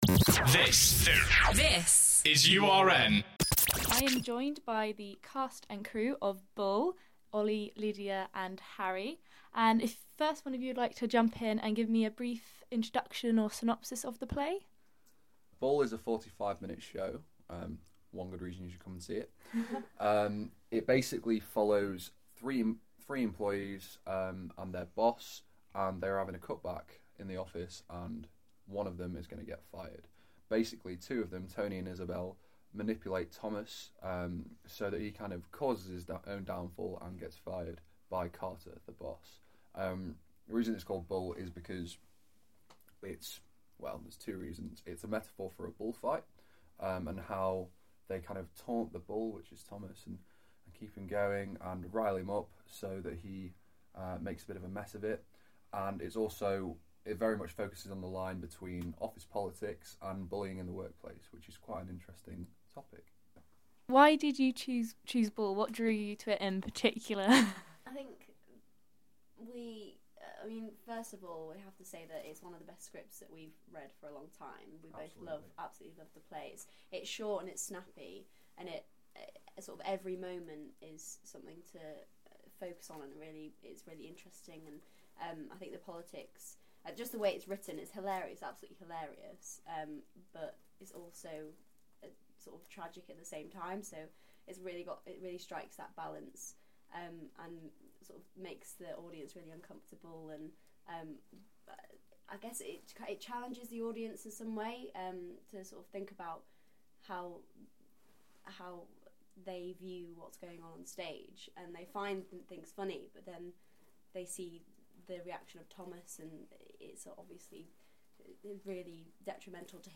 URN interviews the cast and crew of the Oliver award winning play Bull '
This week the Culture Show interviewed some of the cast and crew of Bull, an Oliver award winning play by Mike Bartlett that will be coming to the New Theatre this Wednesday.